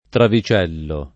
travicello [ travi ©$ llo ] s. m.